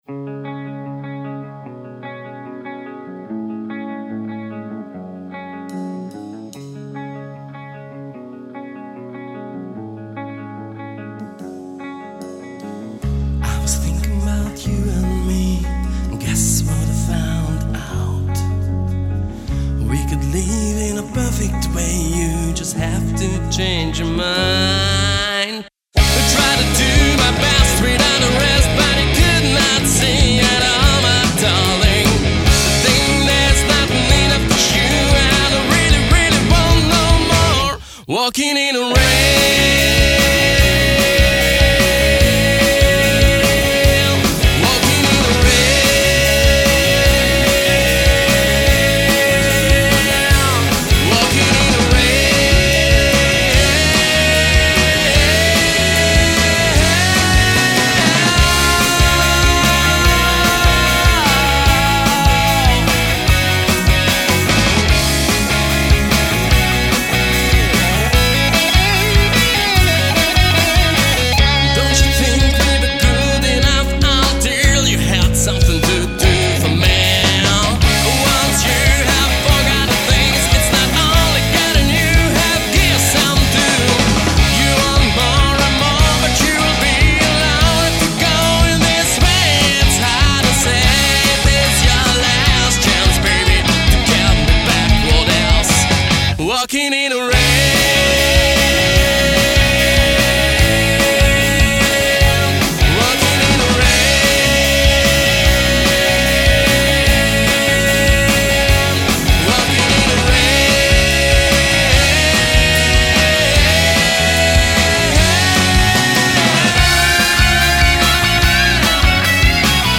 pörgős és friss hangzással
pop-rock